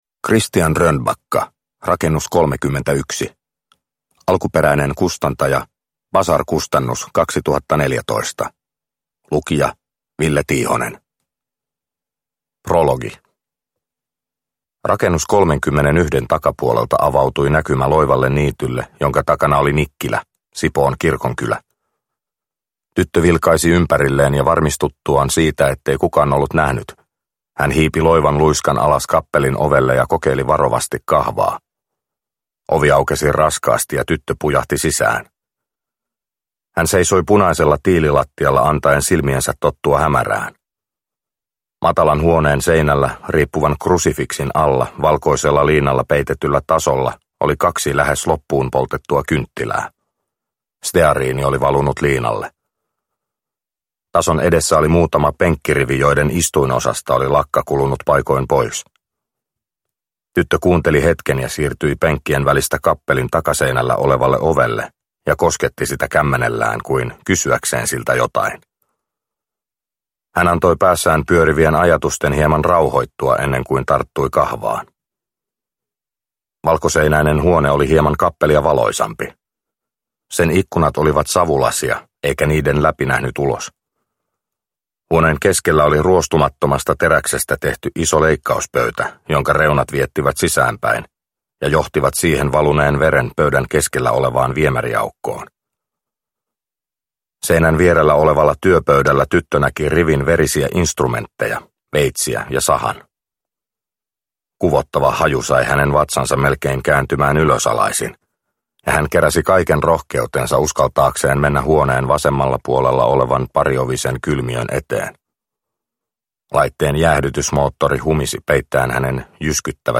Rakennus 31 – Ljudbok – Laddas ner